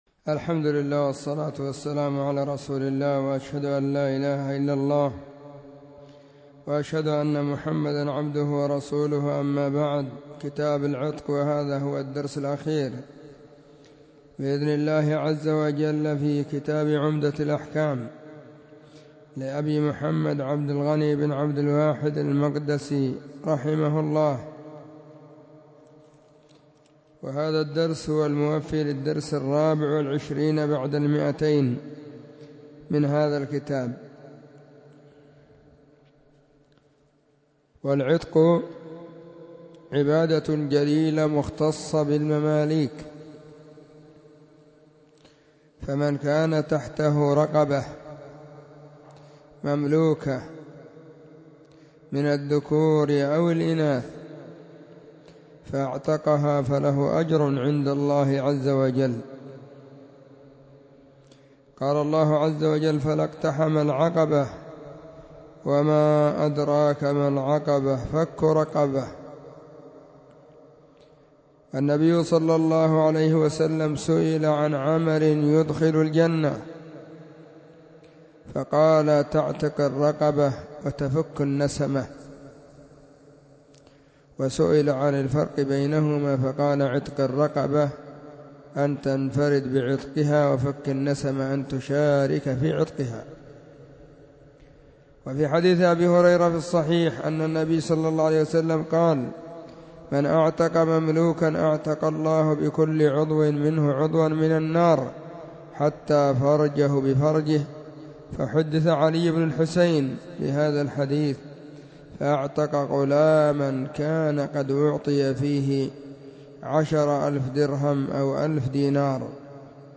🕐 [بعد صلاة العصر] 🕌 في مركز السنة في مسجد الصحابة – بالغيضة – المهرة، اليمن حرسها الله.